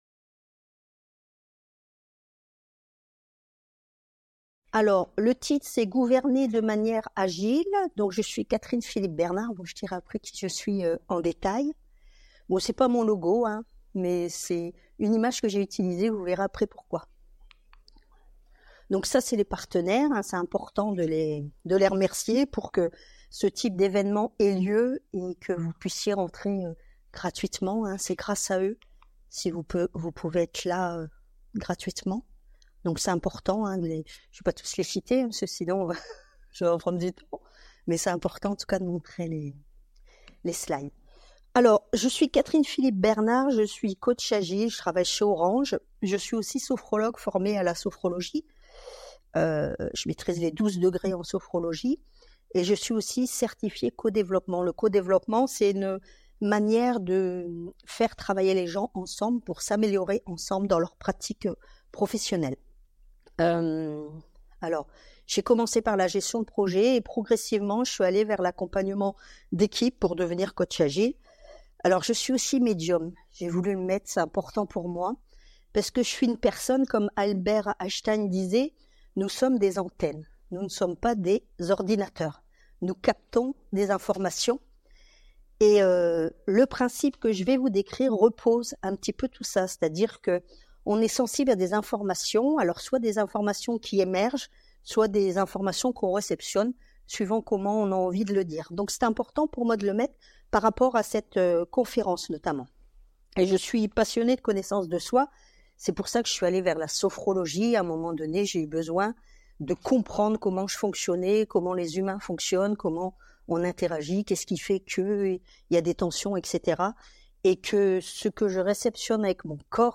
Le Printemps agile 2024 fait son cinéma Conférence